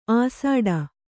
♪ āsāḍa